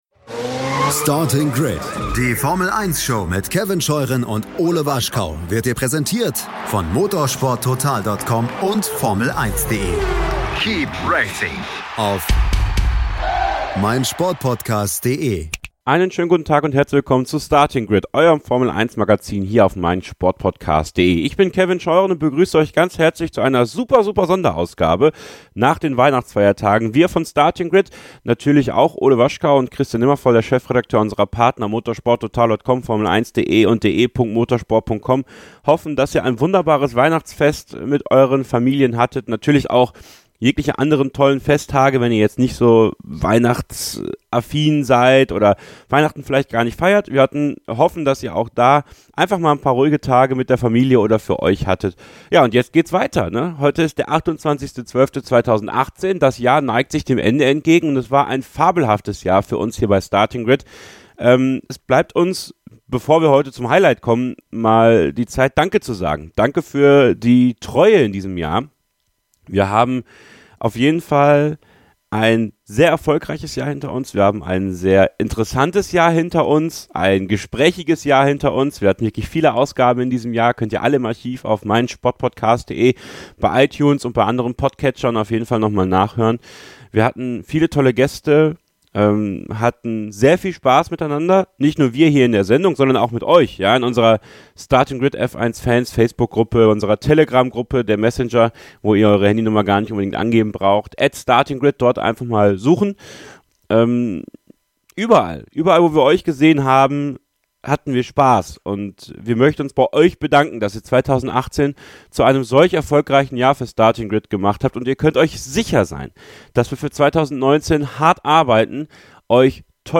to-be-honest-olivier-panis-im-interview.mp3